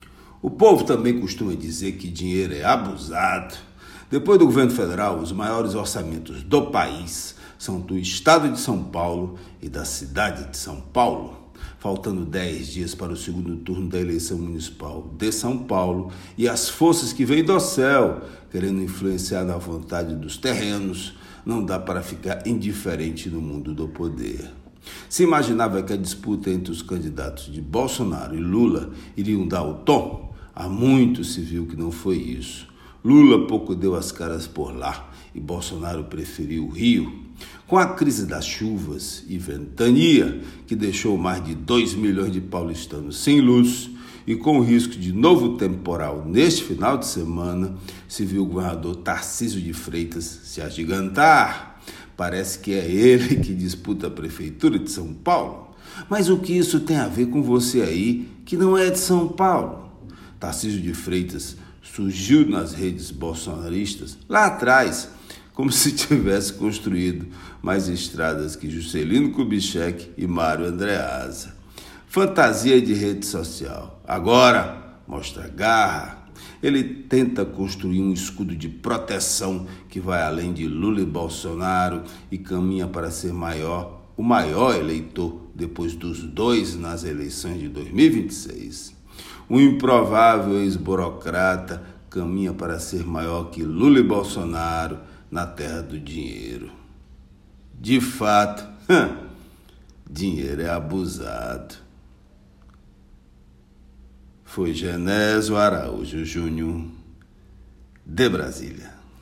Comentário desta sexta-feira
direto de Brasília.